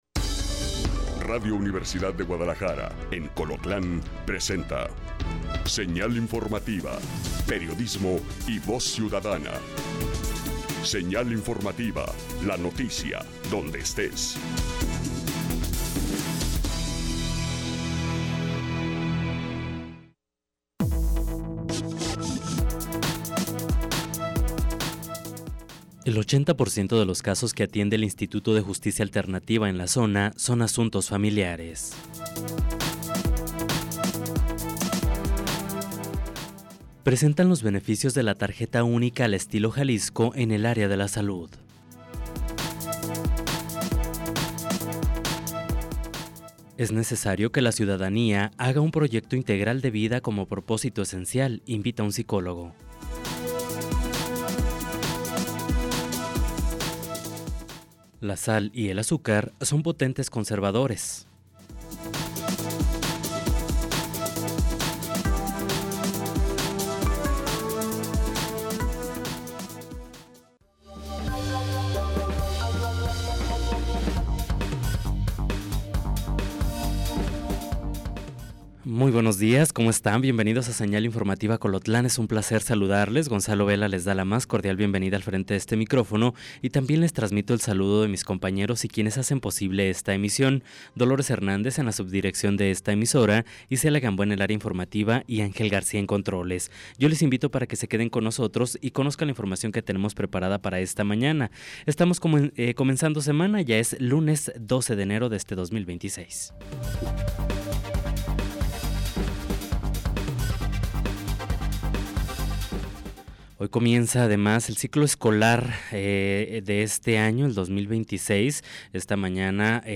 En este noticiero, nos enfocamos en las noticias locales que afectan directamente su vida y su entorno. Desde políticas y eventos comunitarios hasta noticias de última hora y reportajes especiales.